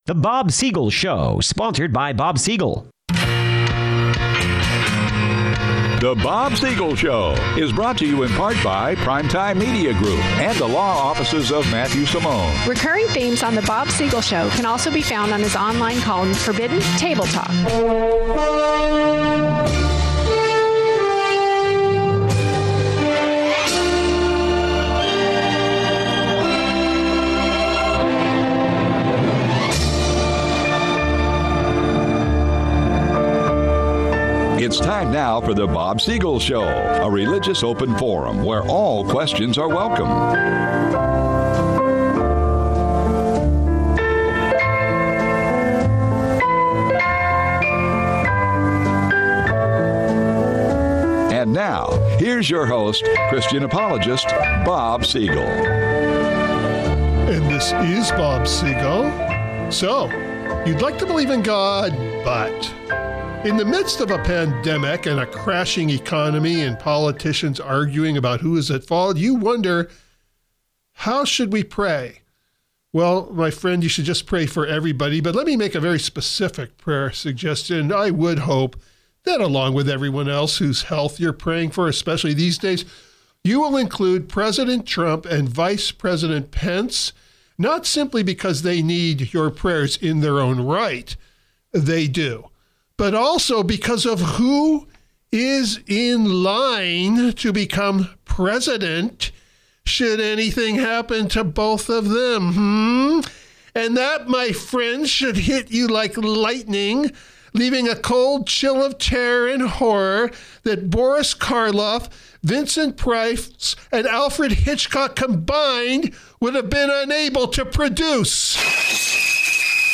A great deal of humor and satire is offered as a means of easing tensions related to the seriousness.